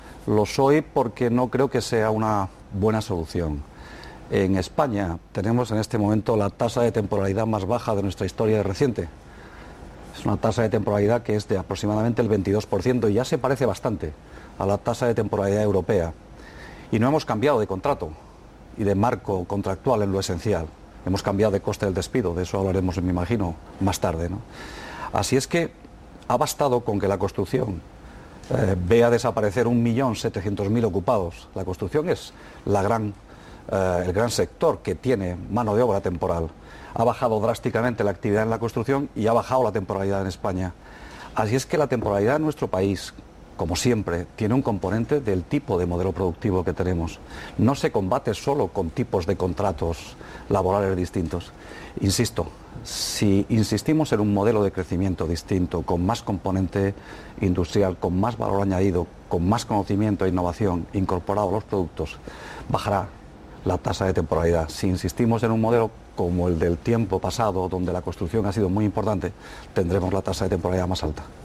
Valeriano Gómez, portavoz de empleo, en los Desayunos de TVE. 14/05/2013